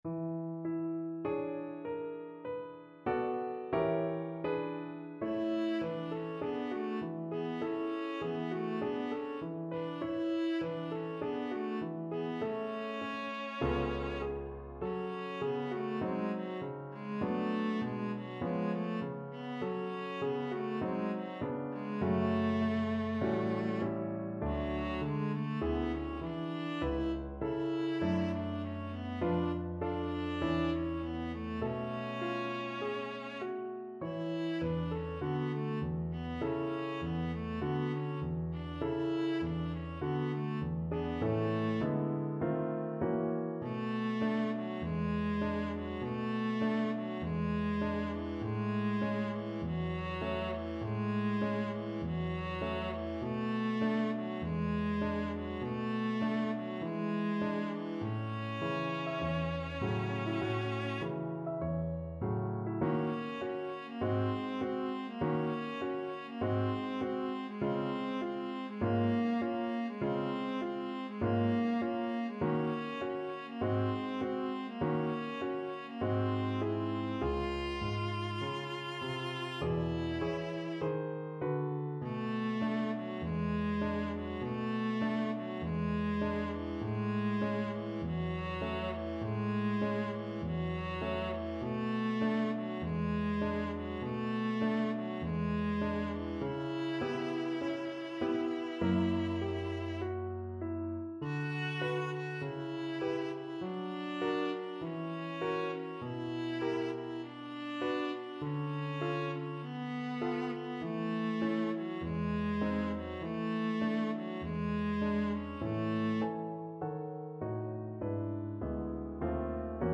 4/4 (View more 4/4 Music)
Moderato
Pop (View more Pop Viola Music)